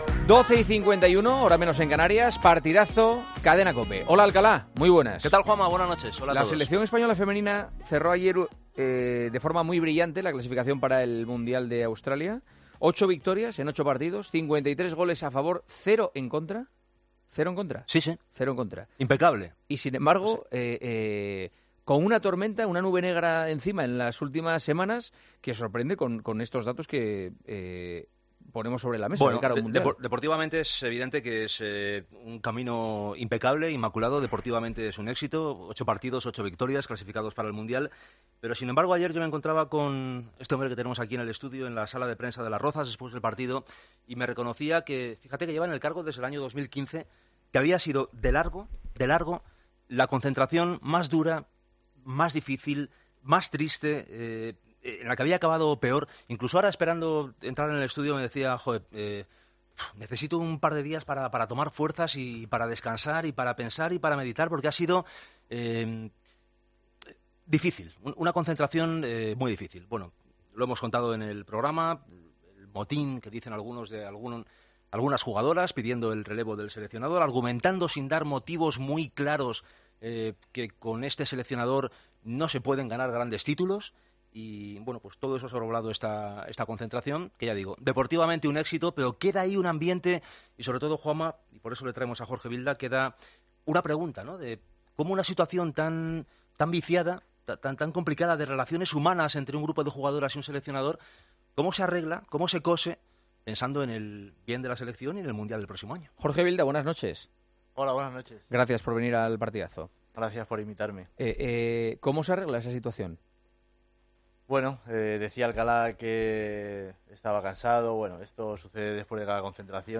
Jorge Vilda, seleccionador femenino de fútbol, ha pasado este miércoles por los micrófonos de El Partidazo de COPE.